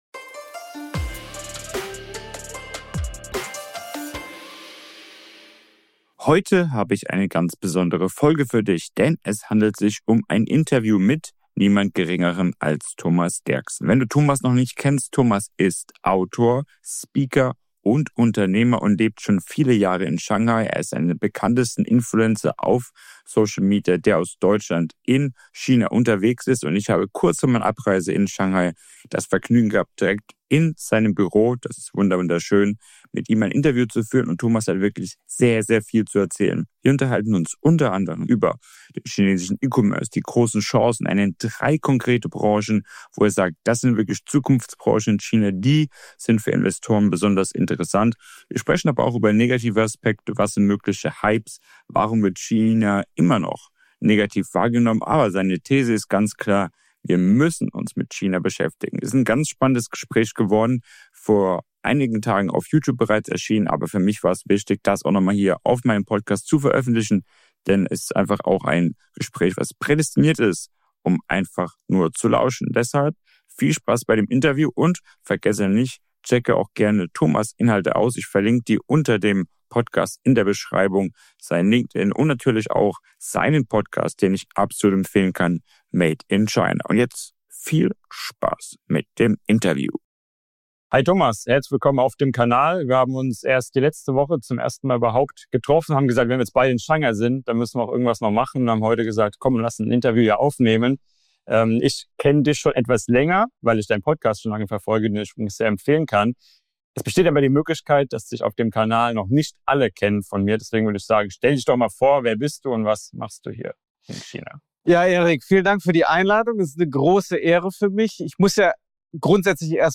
Dies ist eine Interview-Folge, die ursprünglich als Video auf YouTube erschienen ist.